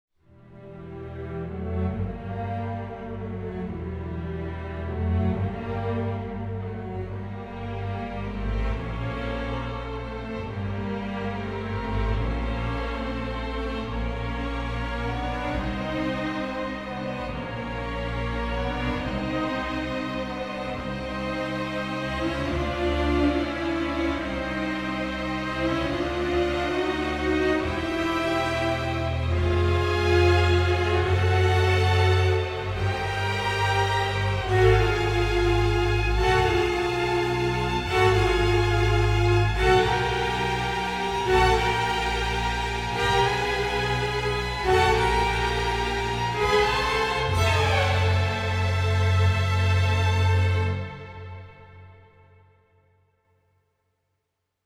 a sultry and suspenseful score